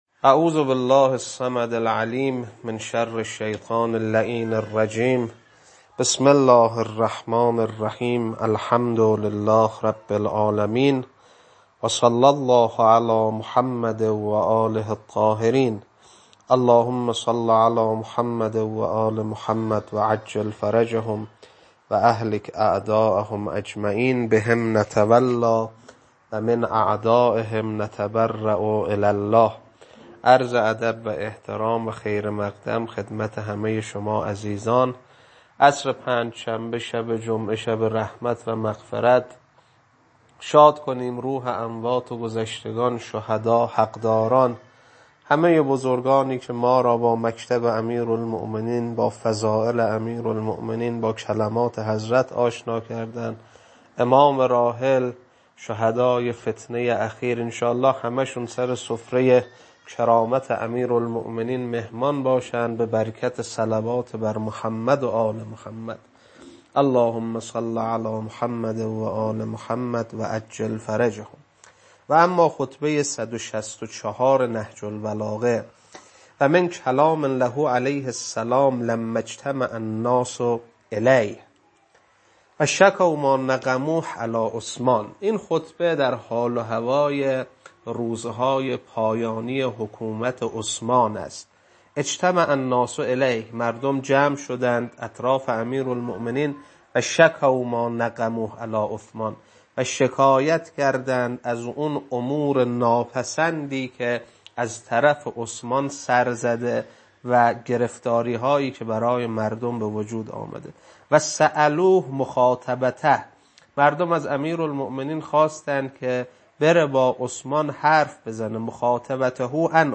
خطبه 164.mp3